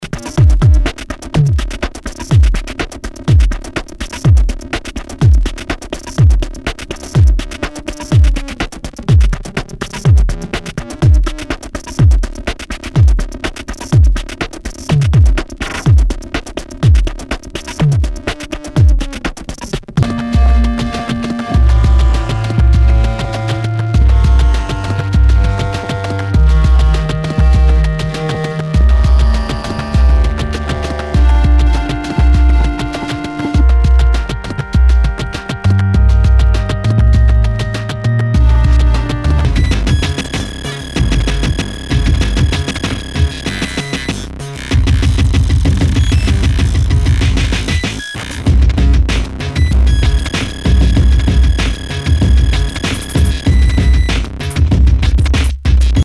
Nu- Jazz/BREAK BEATS
ナイス！IDM / エレクトロ / ブレイクビーツ・テクノ！